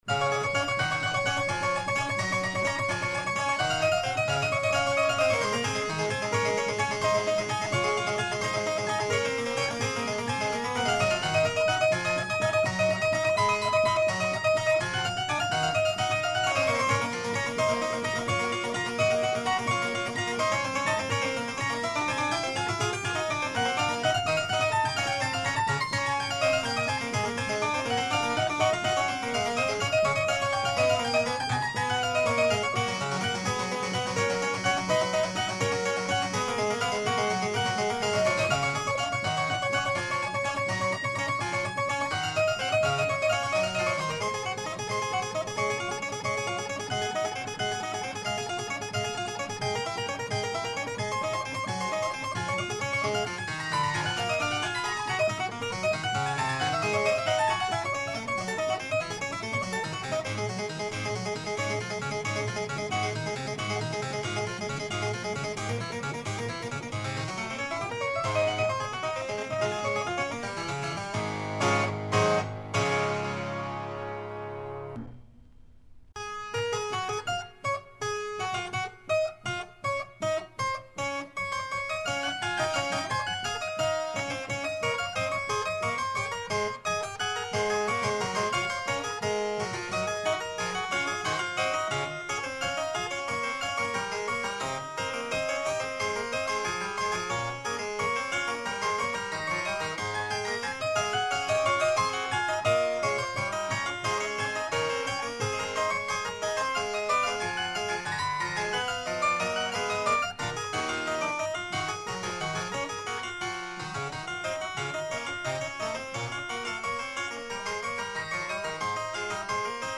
Piano bien tempéré, prélude et fugue en Do# majeur BWV 848, par Jean-Sébastien Bach